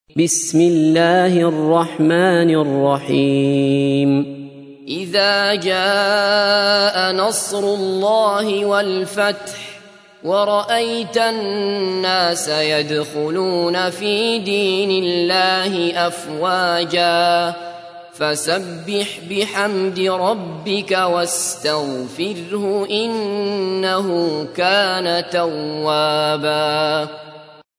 تحميل : 110. سورة النصر / القارئ عبد الله بصفر / القرآن الكريم / موقع يا حسين